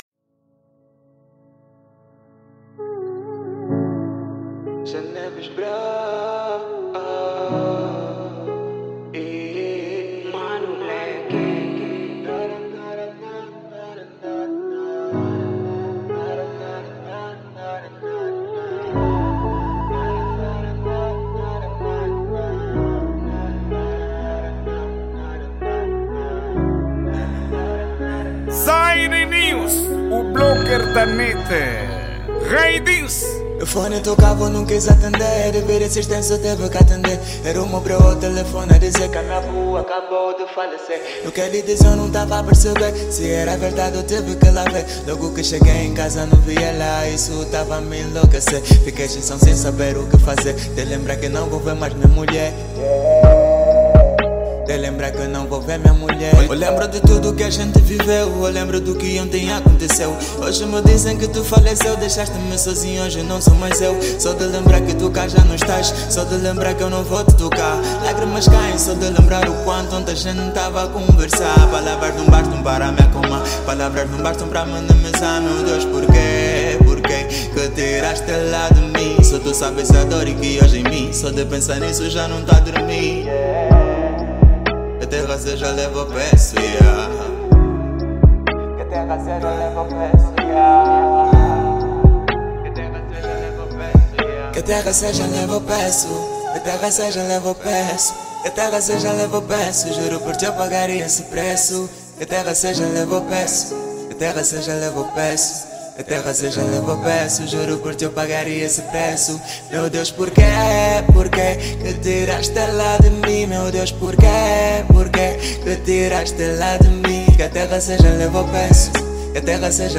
Genero:Rap